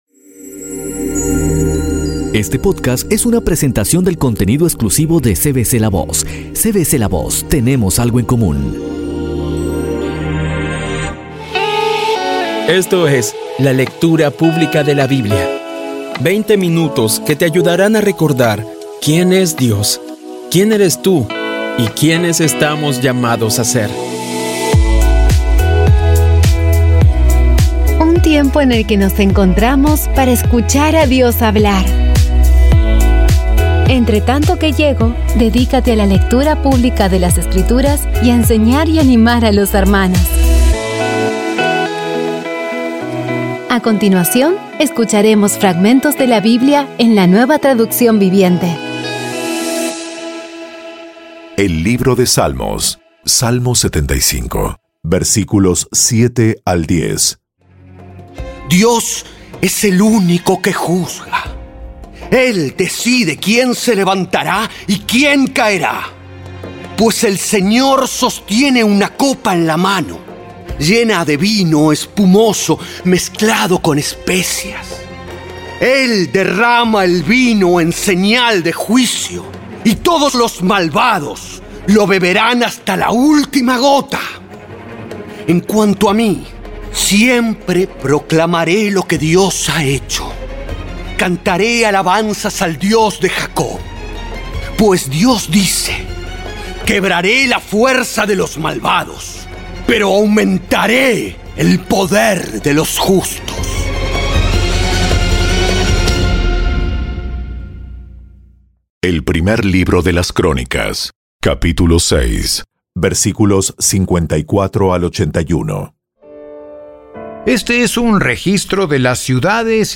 Audio Biblia Dramatizada Episodio 176